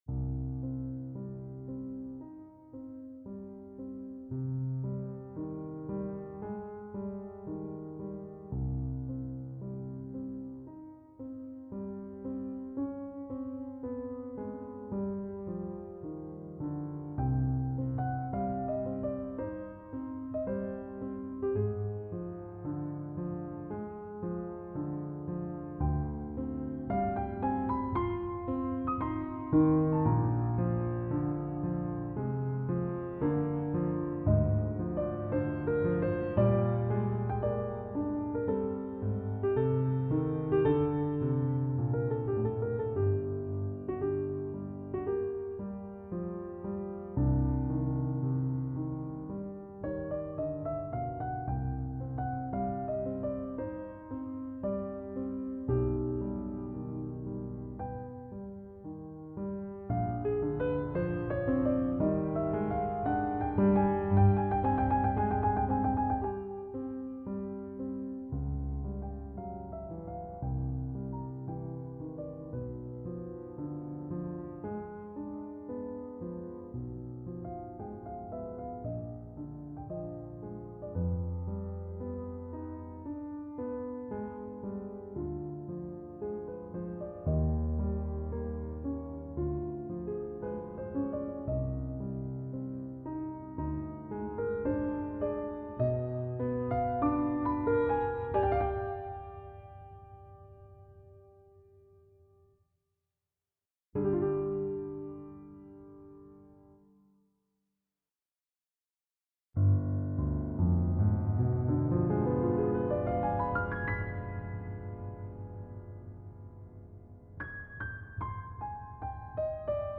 Nocturne no. 1 in C minor - Piano Music, Solo Keyboard - Young Composers Music Forum